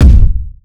Waka KICK Edited (23).wav